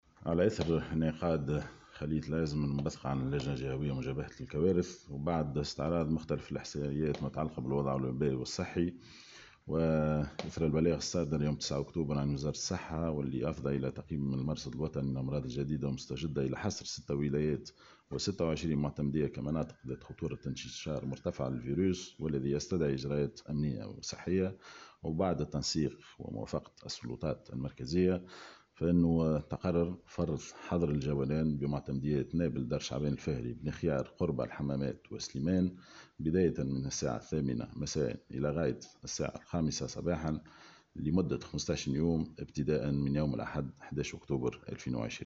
وأضاف في تصريح اليوم لمراسلة "الجوهرة أف أم" أن المعتمديات المعنية بحظر الجولان هي نابل ودار شعبان وبني خيار و الحمامات وسليمان و قربة ) وذلك بعد تزايد عدد الإصابات بفيروس كورونا.